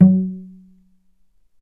healing-soundscapes/Sound Banks/HSS_OP_Pack/Strings/cello/pizz/vc_pz-F#3-mf.AIF at ae2f2fe41e2fc4dd57af0702df0fa403f34382e7
vc_pz-F#3-mf.AIF